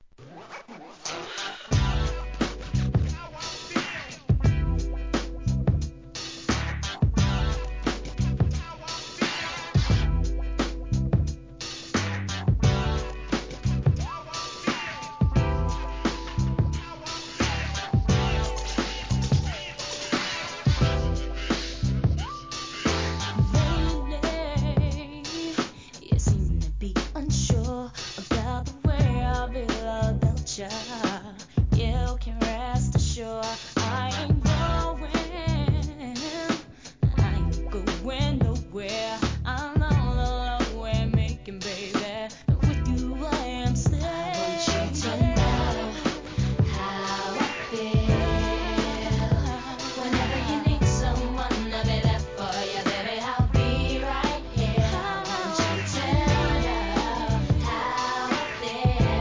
HIP HOP/R&B
UK HIP HOP SOUL!